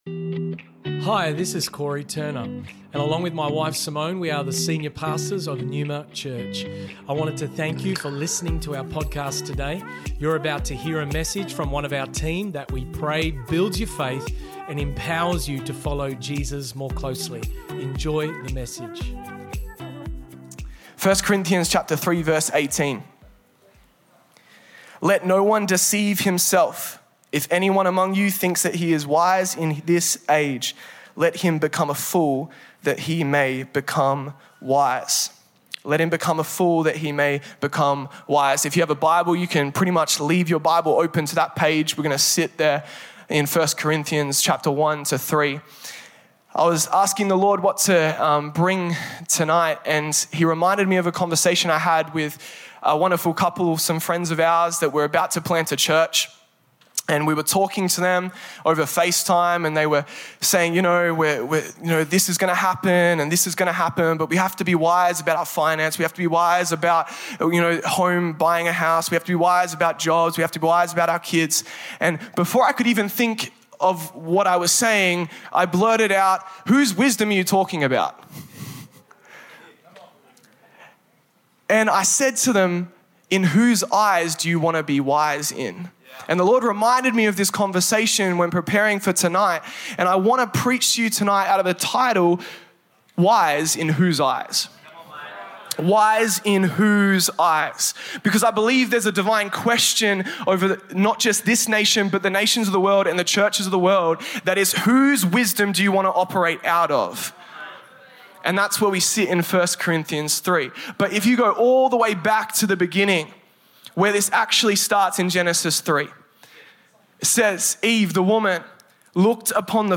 Neuma Church Originally Recorded at the 4PM Revival Service on the 30th April, 2023&nbsp